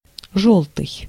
Ääntäminen
IPA: /ɡʉːl/